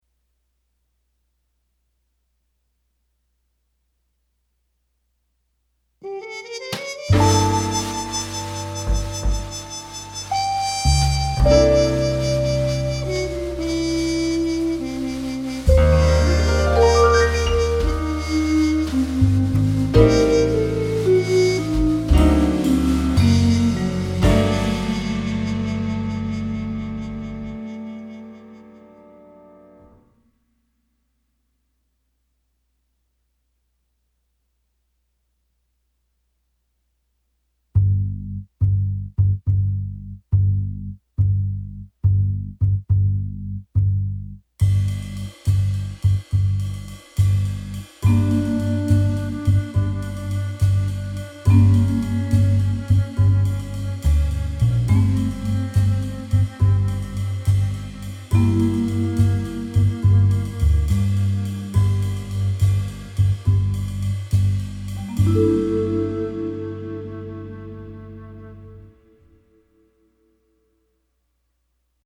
Noir jazz y crime jazz